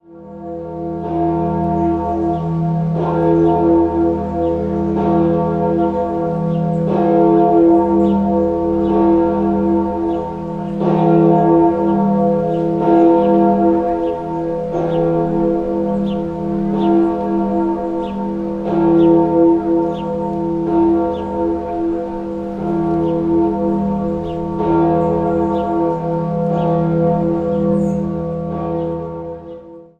Die Freiheitsglocke wird jeden Tag um 12 Uhr geläutet, sowie am 1. Mai, an Heiligabend und zum Jahreswechsel um Mitternacht geläutet. Freiheitsglocke e° Die Glocke wiegt rund 10.200 kg und wurde von der britischen Gießerei Gillett & Johnston im Jahr 1950 gegossen.
Berlin Freiheitsglocke.mp3